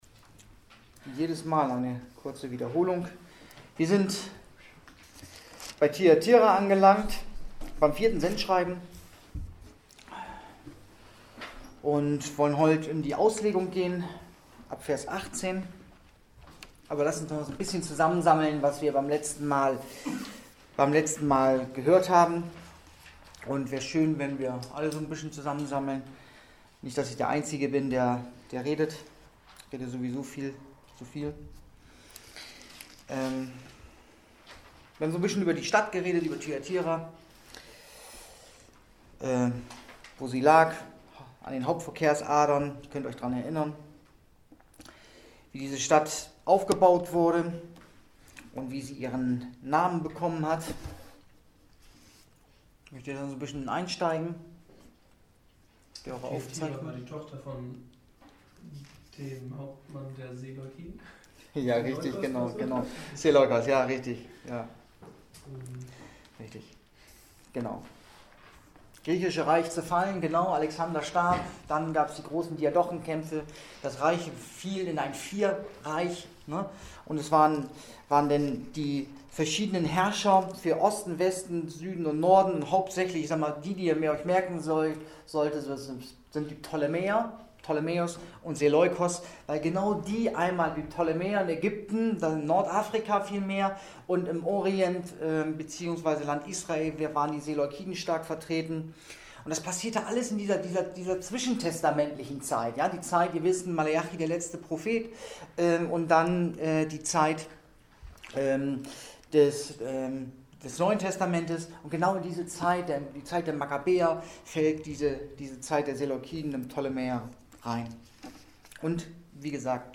Predigt 01.10.2020